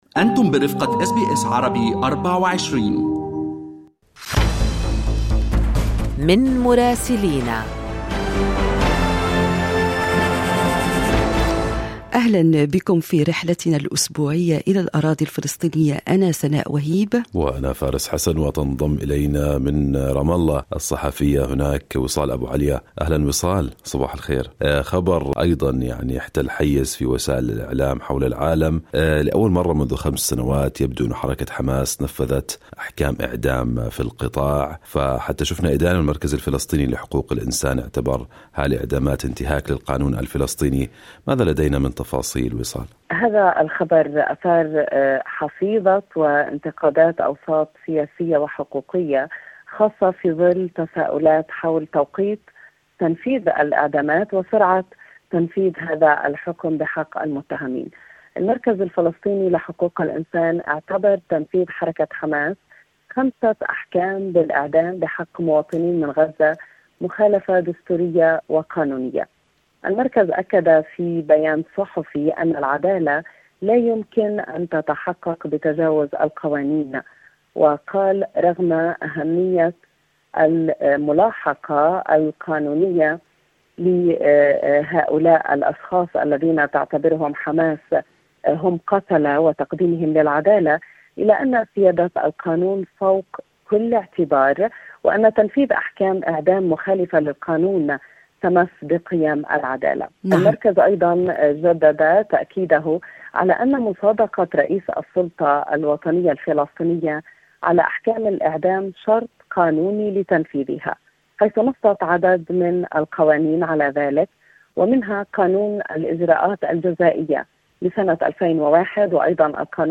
يمكنكم الاستماع إلى التقرير الصوتي من رام الله بالضغط على التسجيل الصوتي أعلاه.